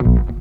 G_07_Bass_02_SP.wav